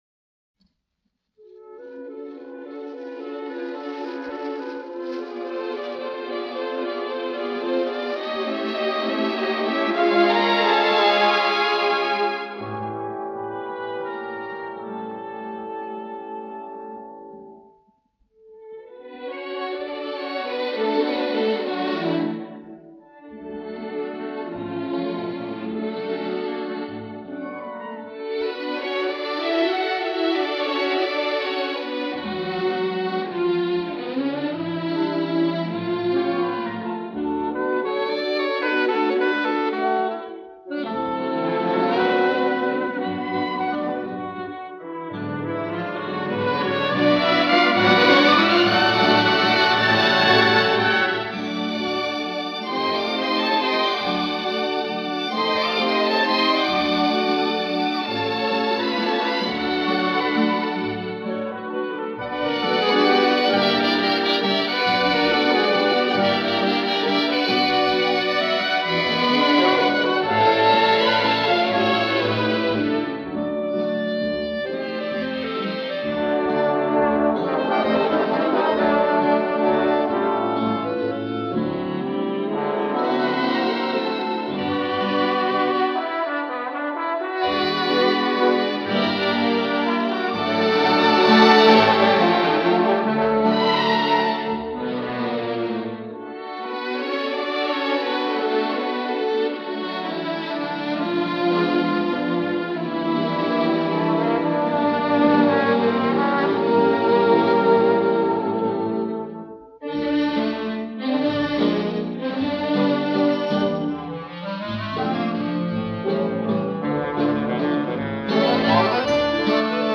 1960 год. 1. Орк.